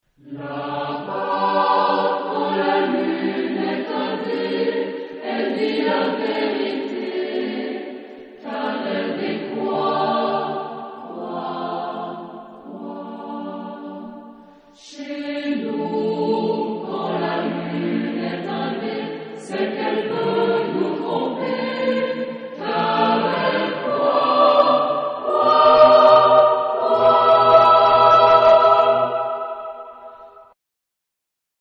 Genre-Style-Forme : Profane ; Cycle
Caractère de la pièce : poétique
Type de choeur : SATB  (4 voix mixtes )
Tonalité : sol majeur